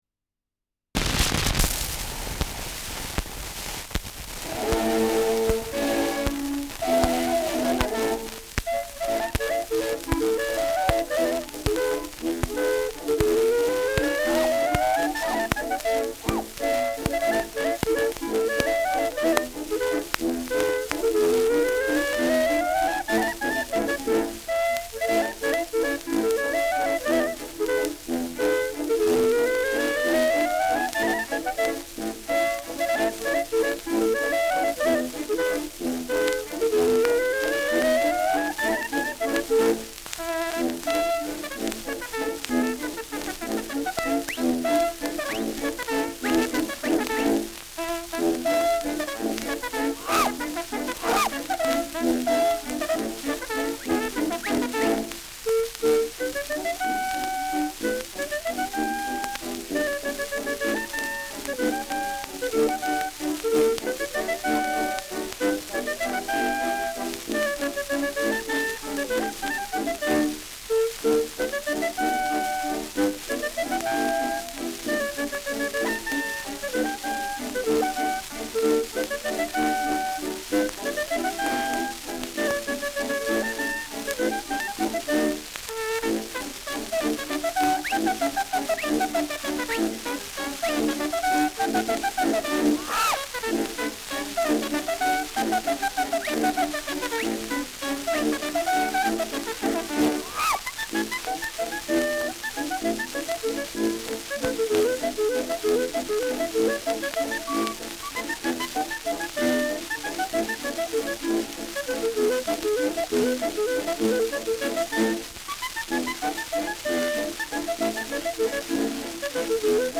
Schellackplatte
Starkes Grundrauschen : Zu Beginn starkes Knacken : Gelegentlich leichtes Knacken
Militärmusik des k.b. 14. Infanterie-Regiments, Nürnberg (Interpretation)